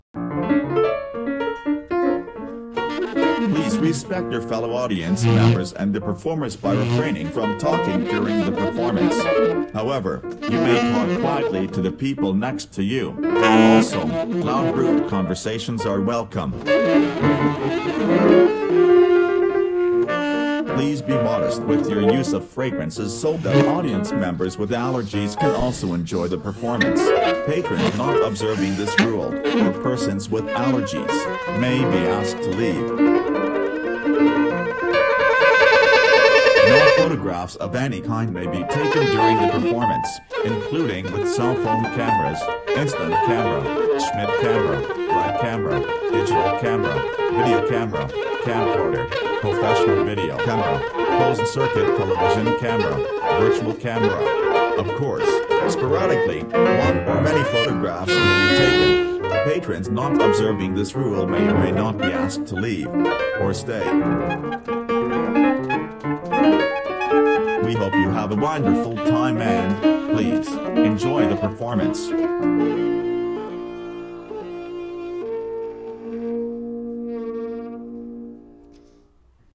piano, toys, reading
tenor saxophone, reading
bass, reading
drums, reading Audience at Spectrum - toys, sounds